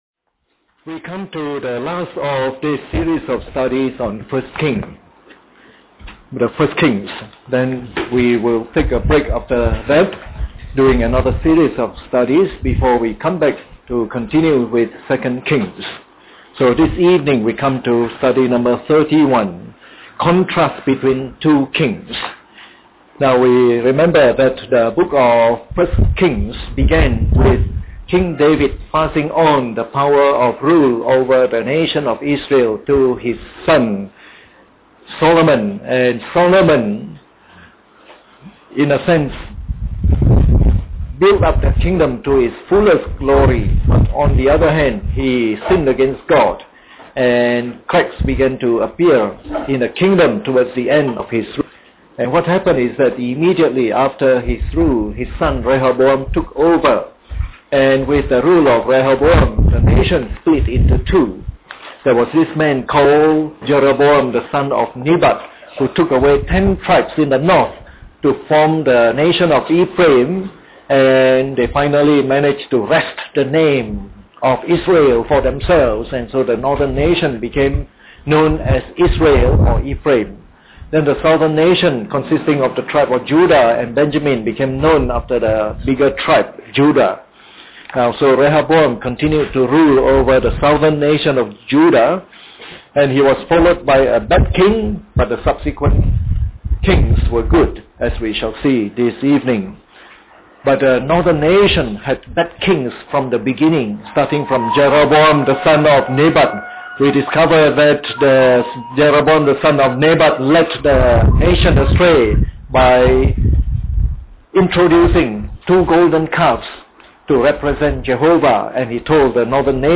Preached on the 26th of November 2008. Part of the “1 Kings” message series delivered during the Bible Study sessions.